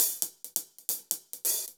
Index of /musicradar/ultimate-hihat-samples/135bpm
UHH_AcoustiHatA_135-01.wav